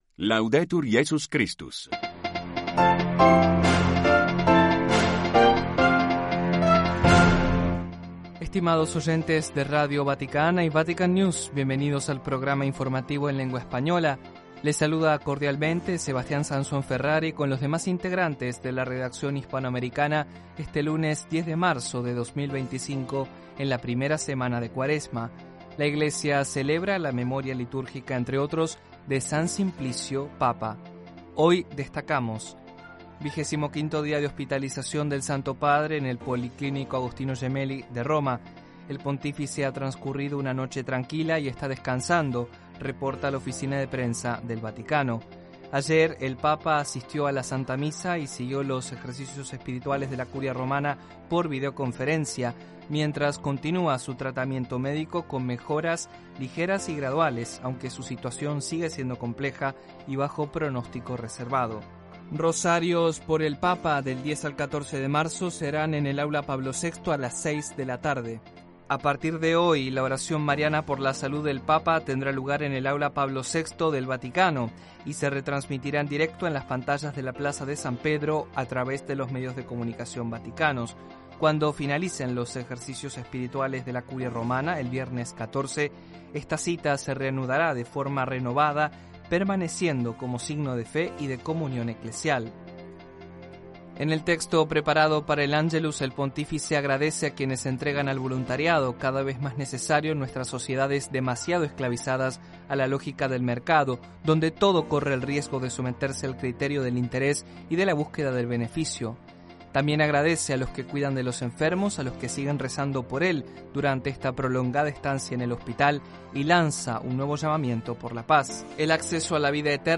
Informativos diarios en español